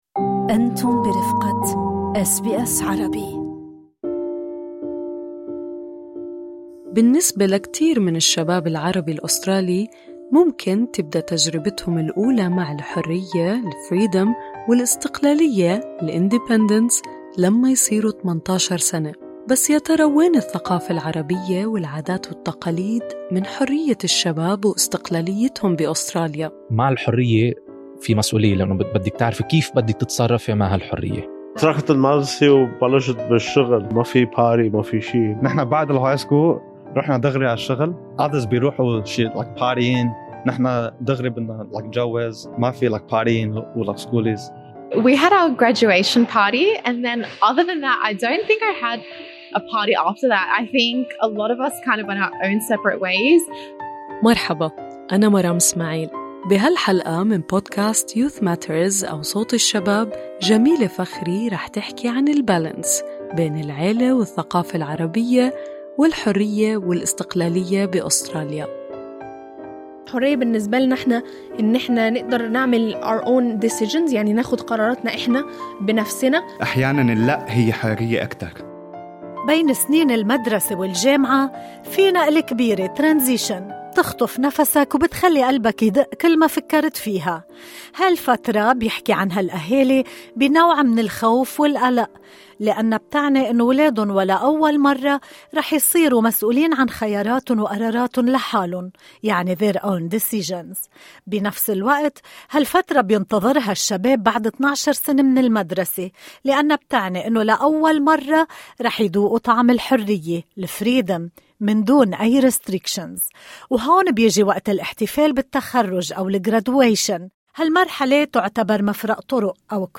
في هذه الحلقة من بودكاست Youth Matters أو "صوت الشباب"، نصغي لشباب وشابات من خلفية عربية مرّوا بهذه المرحلة التحوّلية. من خلال حوارات صريحة، يشاركون قصصهم، ويتأملون في تجاربهم، ويكشفون عن المعنى الحقيقي للحرية بالنسبة لهم.